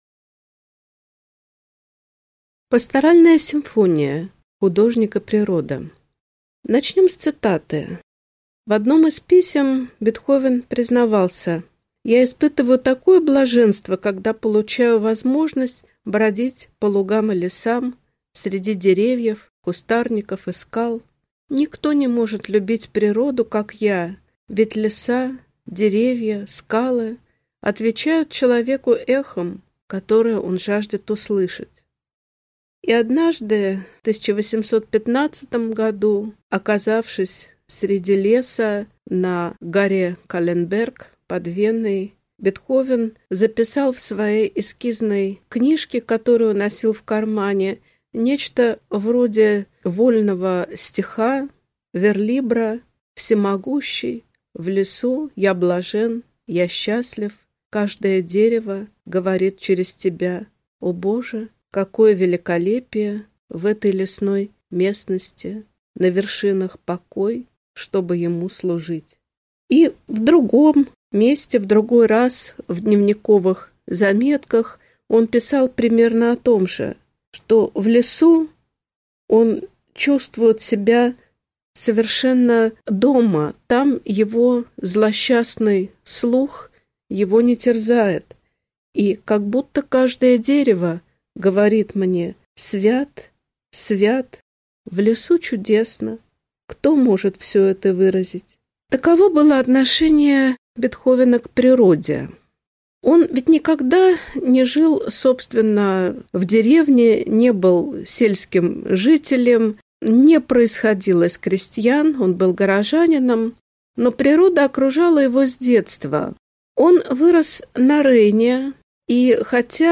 Аудиокнига Лекция «„Пасторальная симфония“: природа и художник» | Библиотека аудиокниг